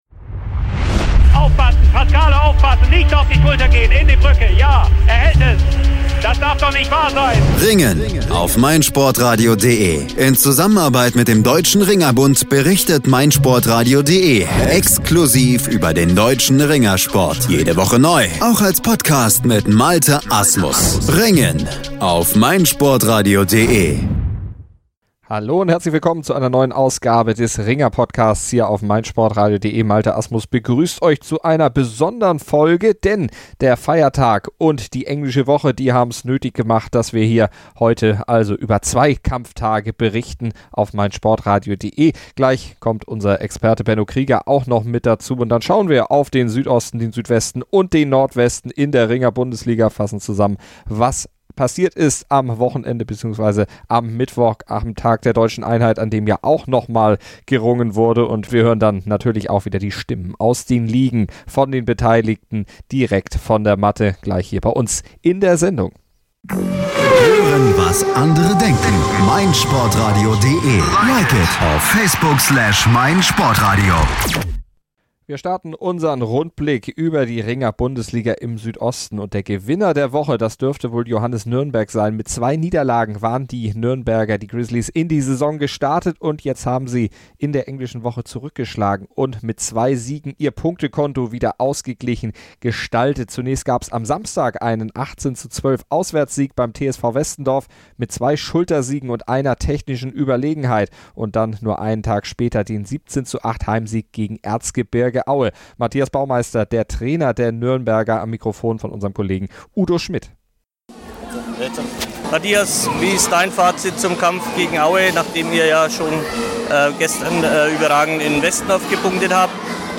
Es gibt eine Menge zu berichten und viele Stimmen aus der Bundesliga zu hören. U.a. von Weltmeister Frank Stäbler.